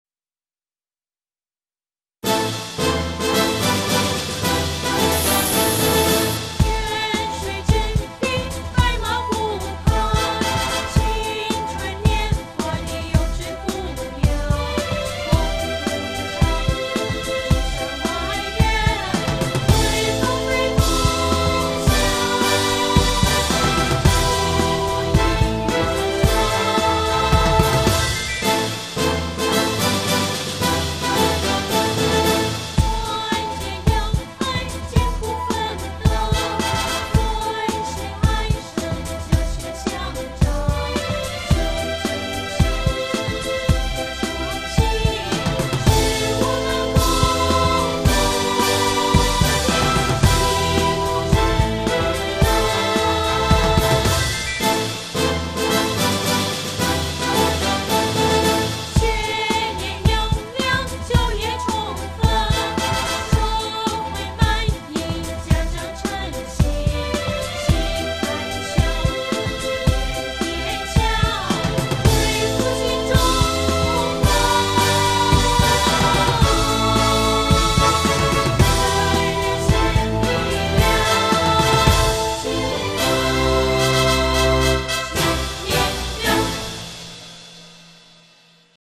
校歌：
常德中山外院院歌(范唱)  另存为